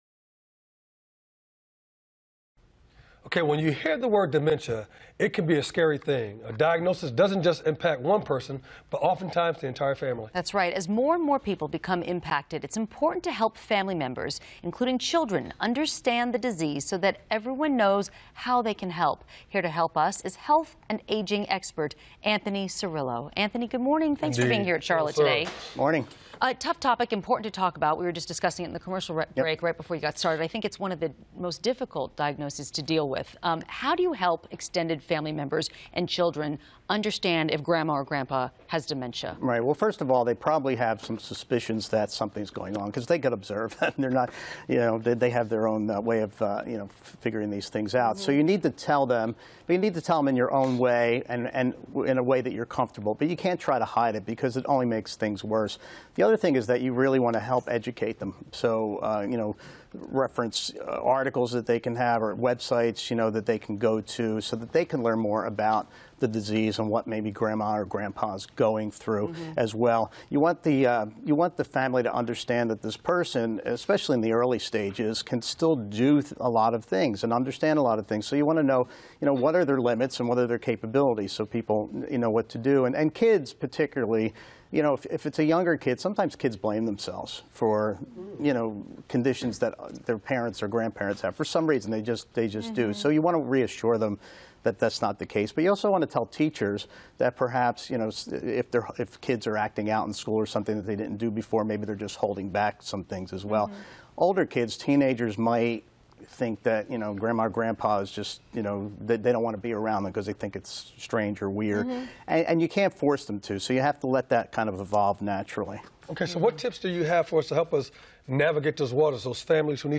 It is from one of my appearances on the Charlotte Today show. We also cover how you tell children about the diagnosis and how reactions may differ with age.